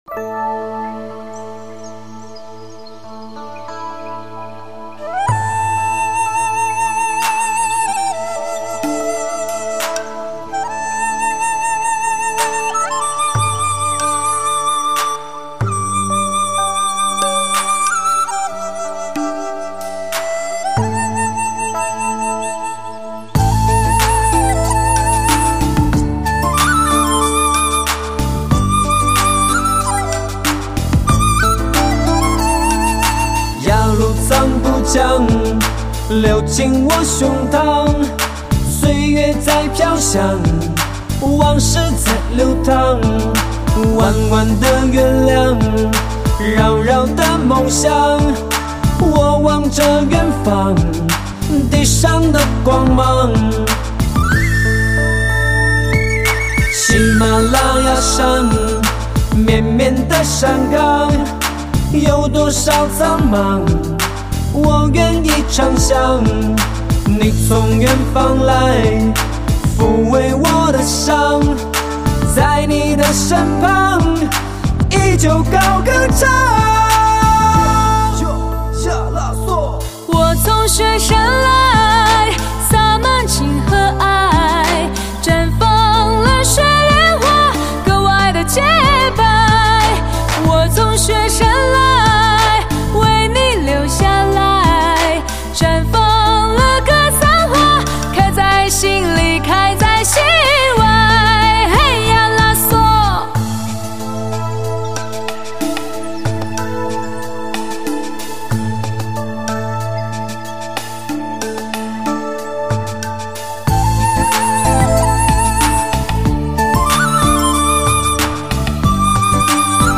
音乐类型: 天籁人声/POP
超魅力女声全新演绎，
……独特的先天嗓音，让听者如痴如醉，用女人的真，歌出意境中的美；在这寒冷的冬天；赠你满满暖暖的爱。
非常好听的 很好听的对唱